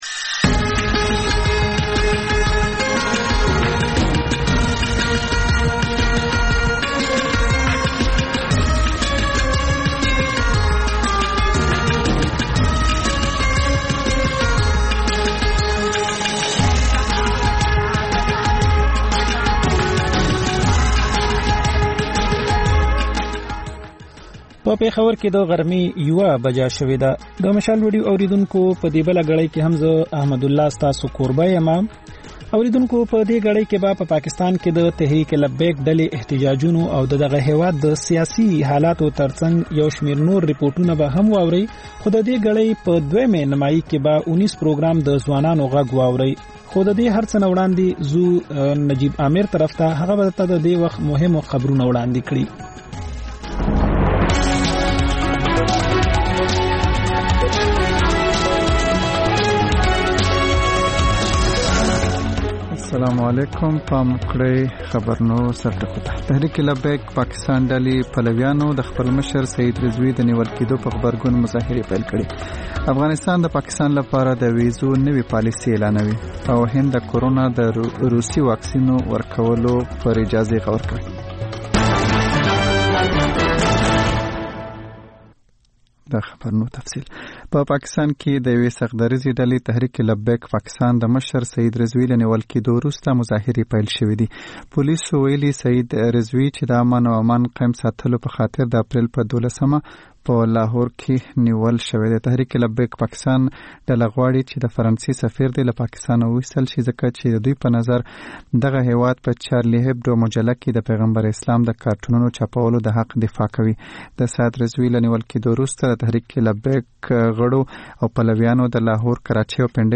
د مشال راډیو لومړۍ ماسپښينۍ خپرونه. په دې خپرونه کې تر خبرونو وروسته بېلا بېل رپورټونه، شننې، مرکې خپرېږي. ورسره یوه اوونیزه خپرونه هم خپرېږي.